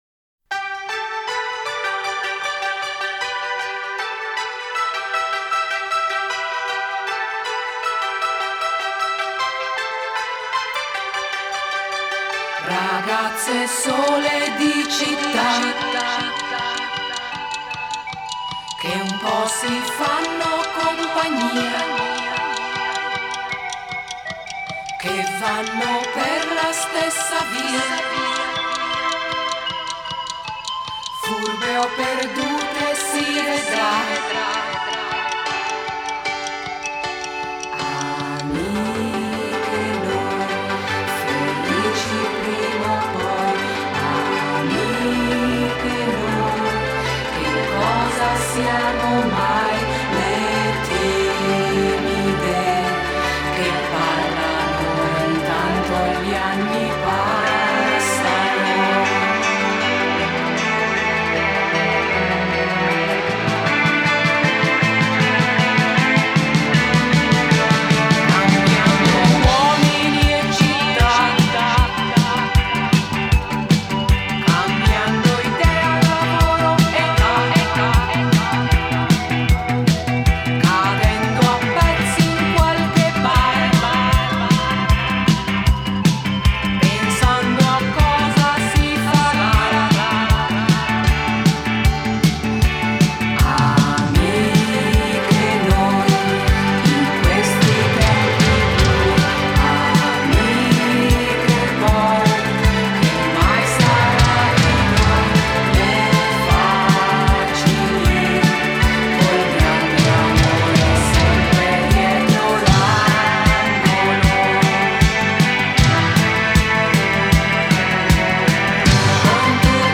Genre: Pop, Disco,Nu-Disco,Dance